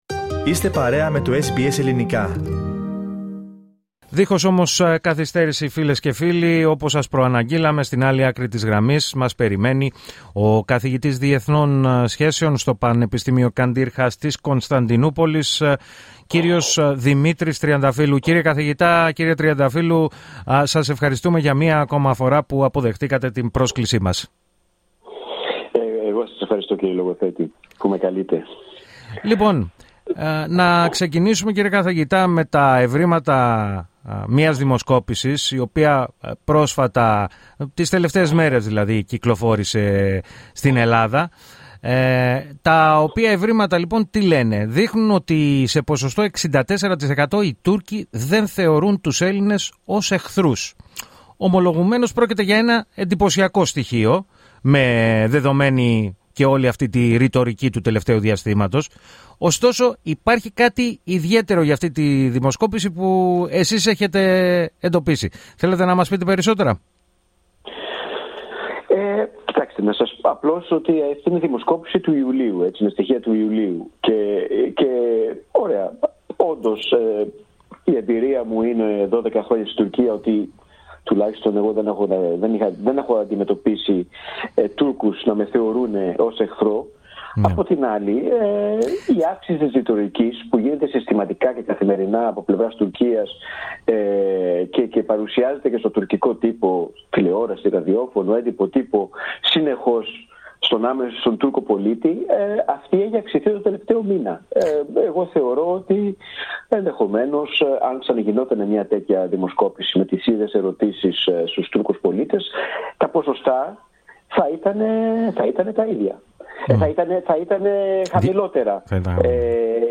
Ακούστε ολόκληρη τη συνέντευξη, κάνοντας double click στο σχετικό ηχητικό.